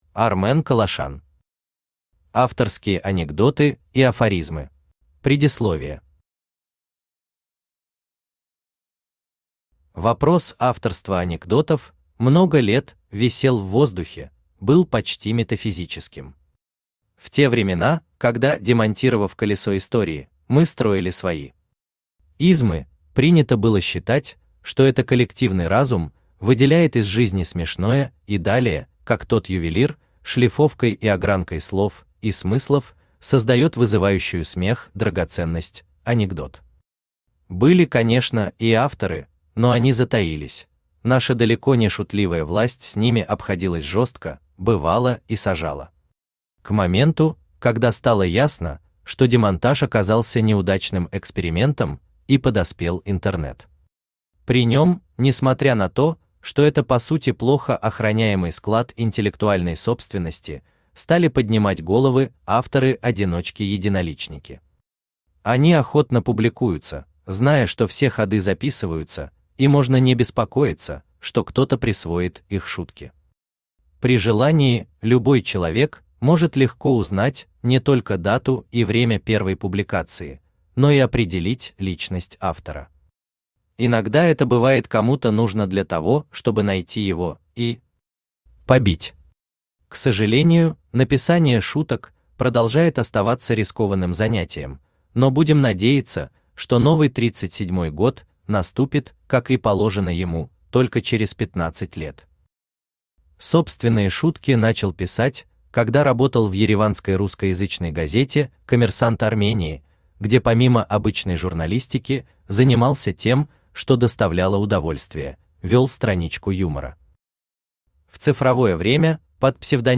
Aудиокнига Авторские анекдоты и афоризмы Автор Армен Калашян Читает аудиокнигу Авточтец ЛитРес.